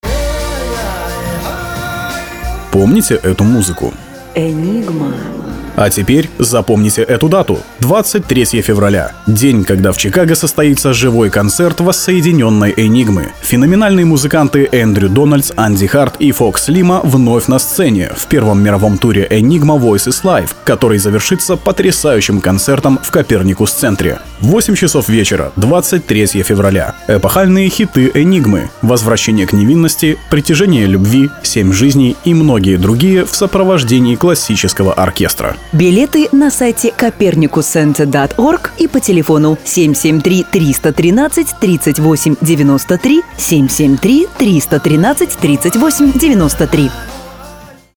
Муж, Рекламный ролик/Средний
Профессиональная студия звукозаписи со всем сопутствующим оборудованием.